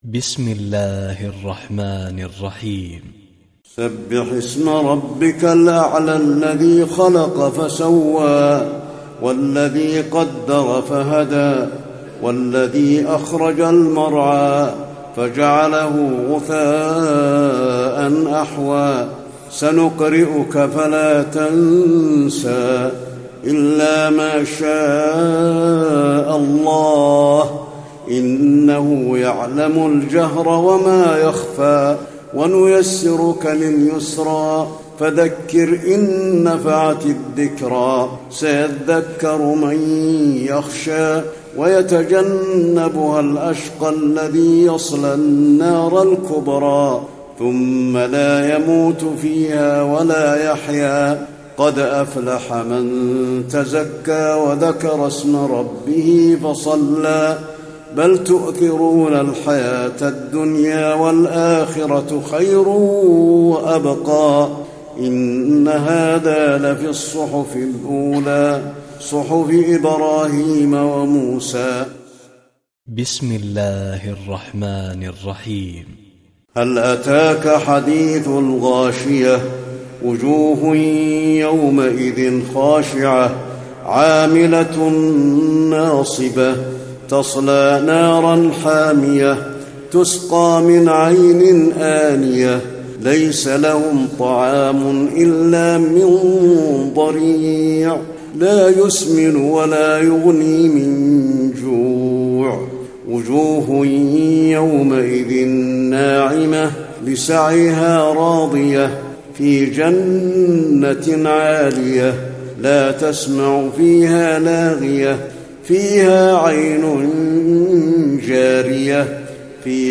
تراويح ليلة 29 رمضان 1435هـ من سورة الأعلى الى الناس Taraweeh 29 st night Ramadan 1435H from Surah Al-A'laa to An-Naas > تراويح الحرم النبوي عام 1435 🕌 > التراويح - تلاوات الحرمين